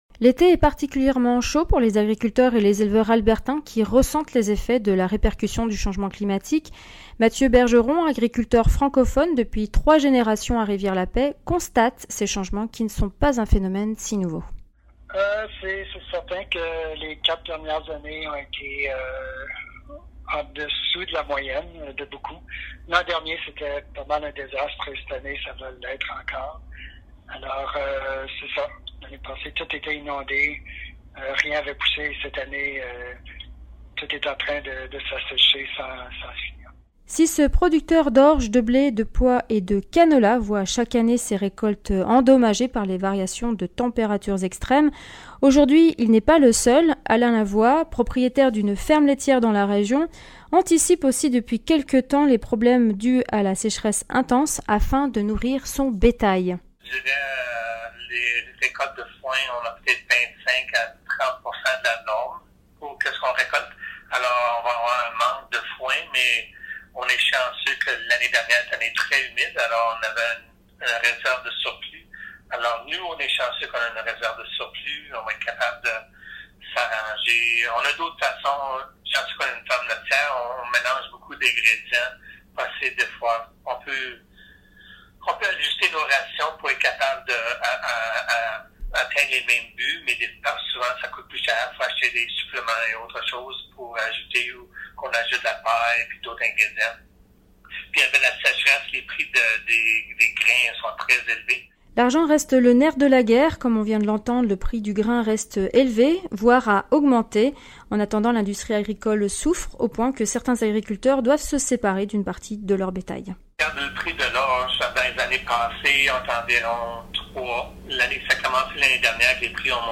Deux agriculteurs de Rivière-la-Paix